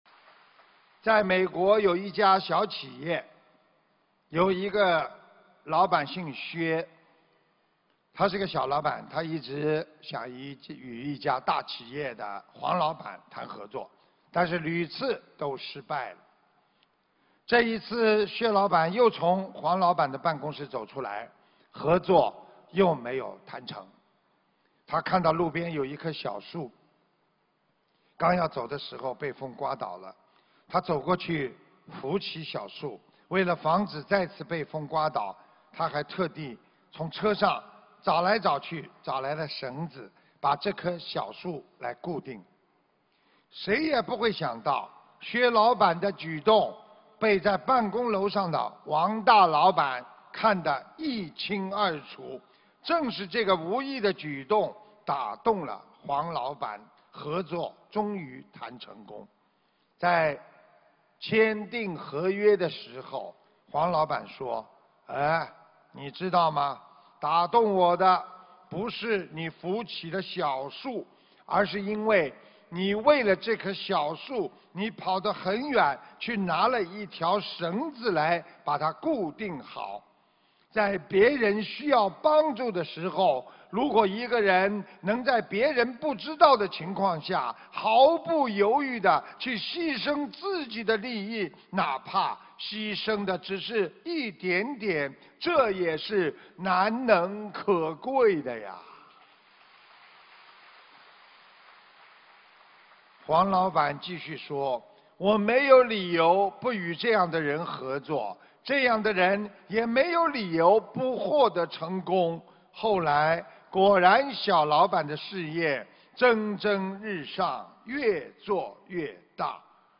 目录：☞ 2018年12月_马来西亚_吉隆坡_开示集锦